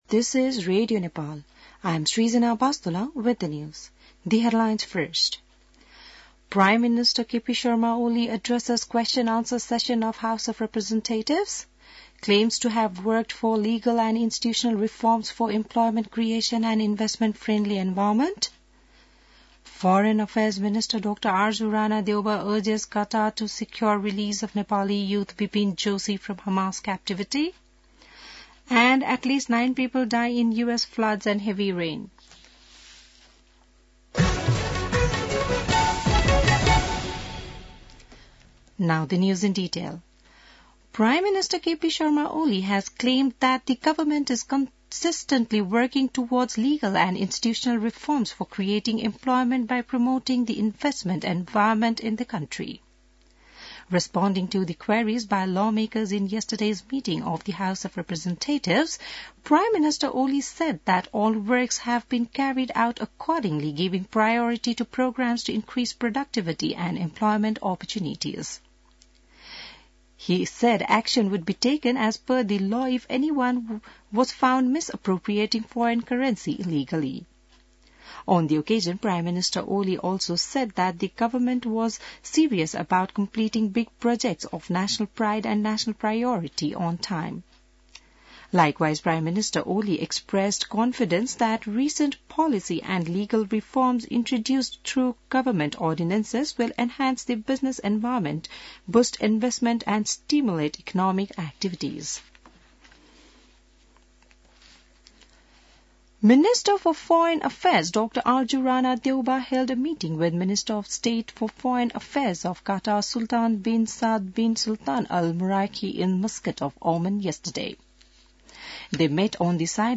An online outlet of Nepal's national radio broadcaster
बिहान ८ बजेको अङ्ग्रेजी समाचार : ६ फागुन , २०८१